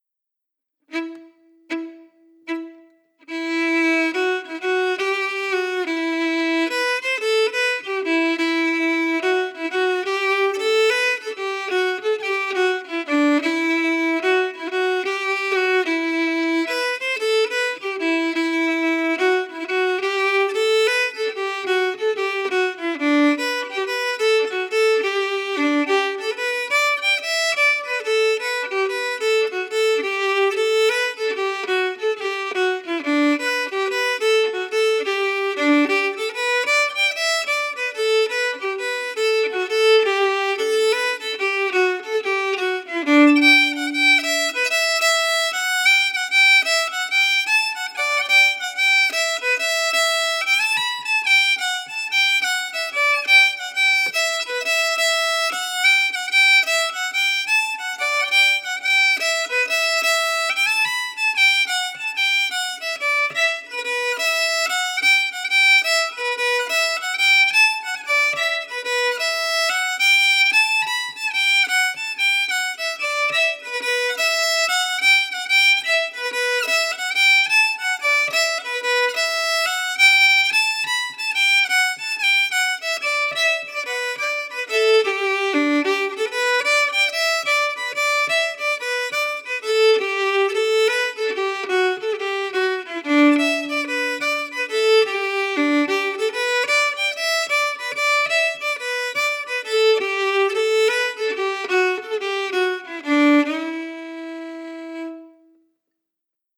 Key: Em
Form: slip Jig
Played slowly for learning
M: 9/8
Genre/Style: Irish slip jig